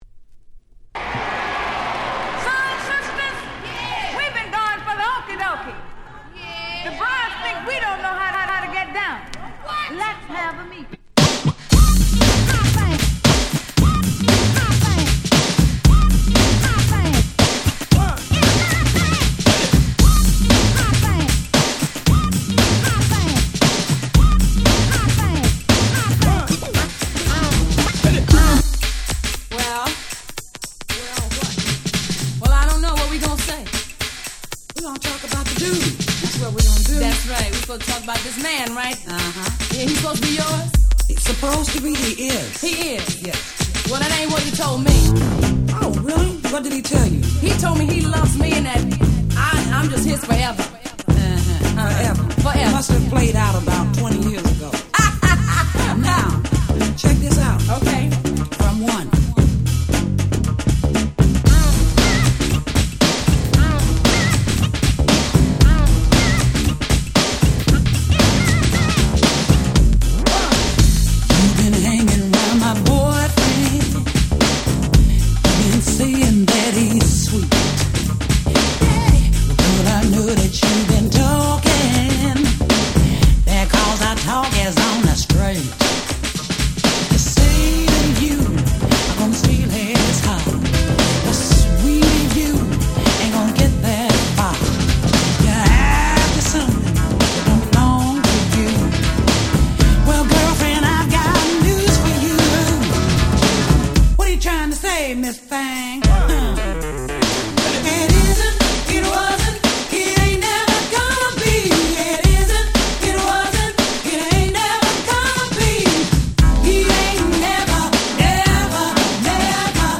89' Big Hit R&B / New Jack Swing !!
バッキバキのGirls Swing !!